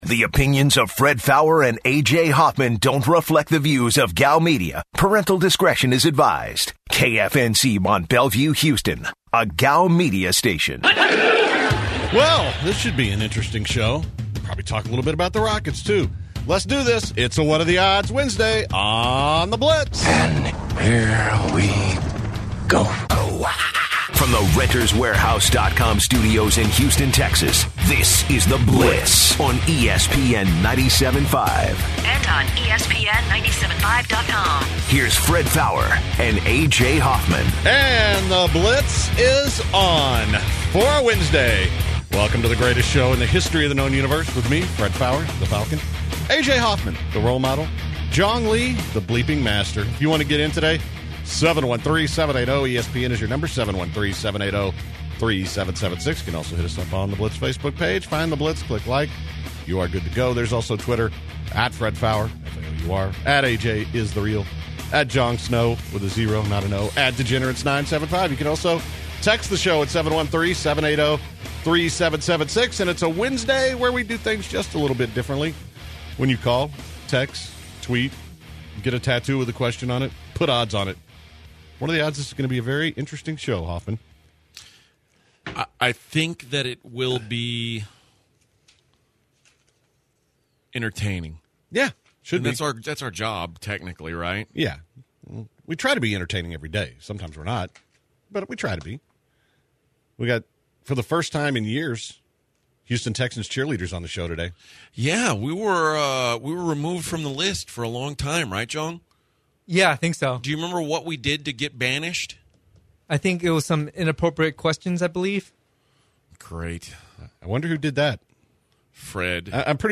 Then, the Houston Texans Cheerleaders come on the show to promote the Cheerleader tryouts. The guys take listeners calls for what are the odds Wednesday.